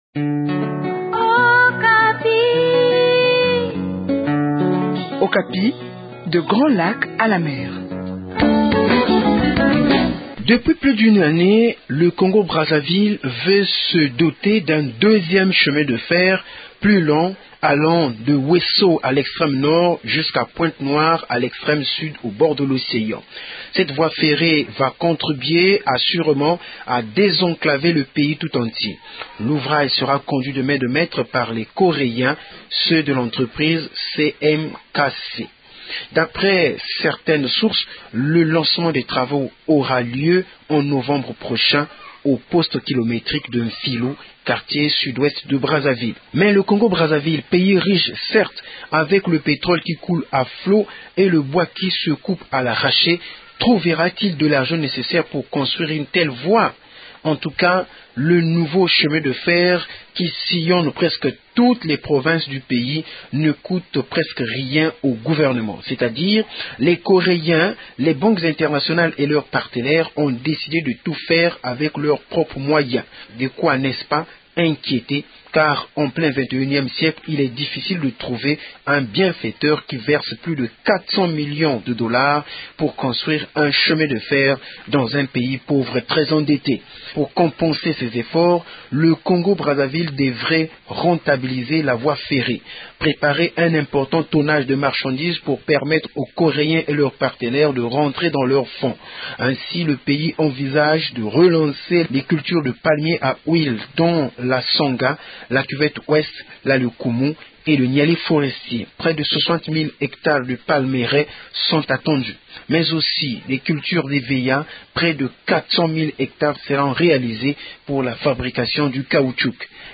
depuis Brazzaville